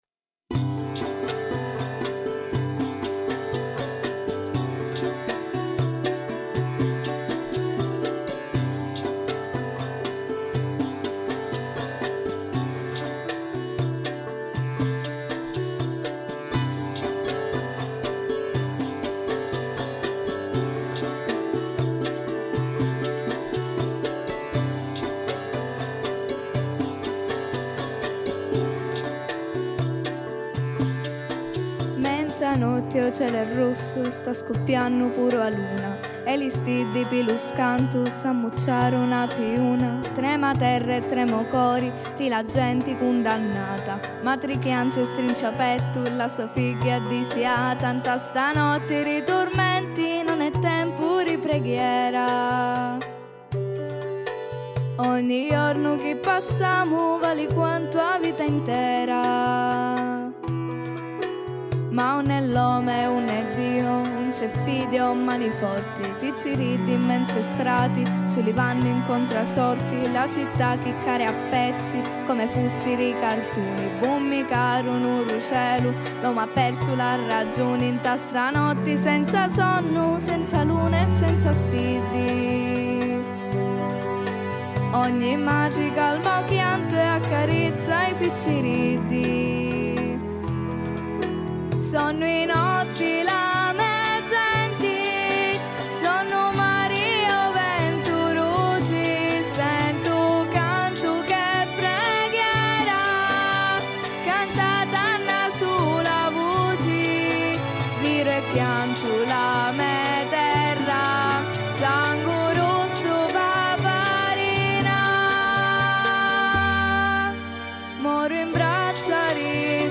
Utilizzando la forza espressiva del dialetto siciliano, la canzone compie un delicato lavoro di memoria, trasformando una vicenda reale e drammatica in una testimonianza universale.